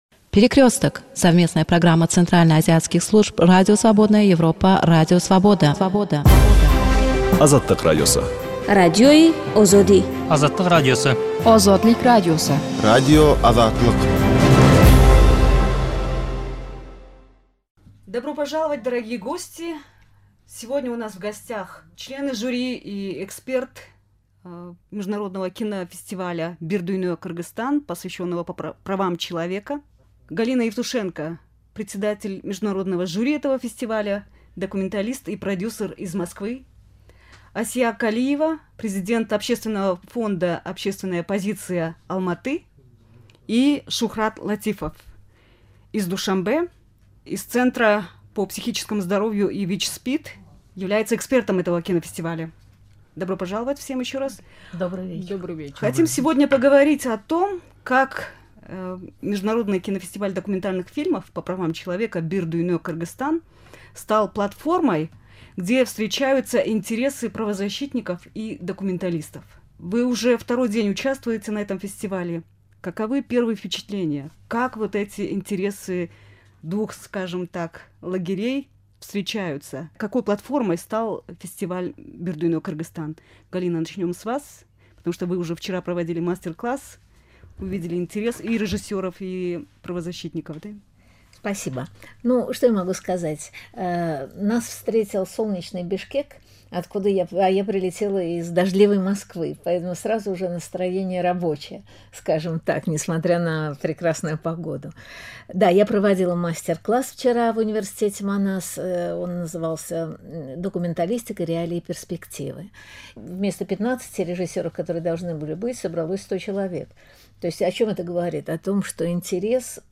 В студии "Азаттыка" члены жюри и эксперты международного кинофестиваля документальных фильмов на правозащитную тематику.